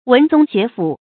文宗學府 注音： ㄨㄣˊ ㄗㄨㄙ ㄒㄩㄝˊ ㄈㄨˇ 讀音讀法： 意思解釋： 文章的宗伯，學問的淵府。比喻學問淵博的人。